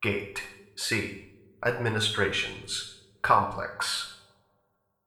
scientist5.ogg